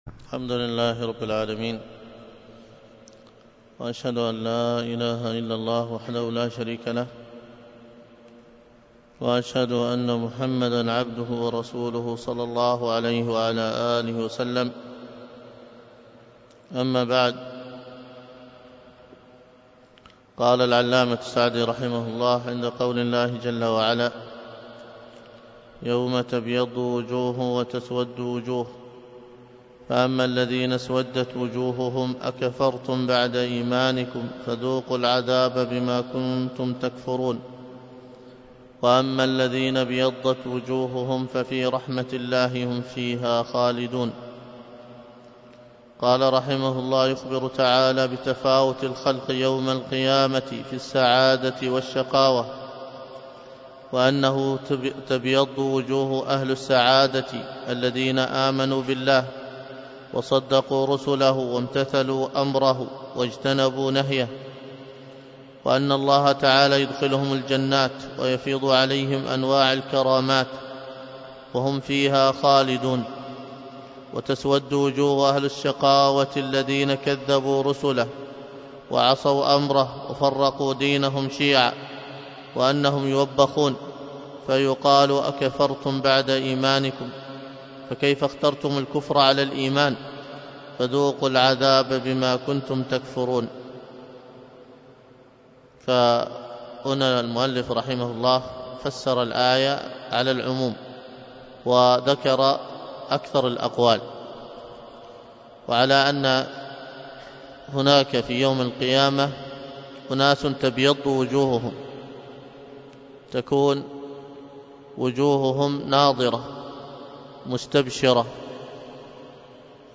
الدرس في تفسير سورة آل عمران من تفسير السعدي 50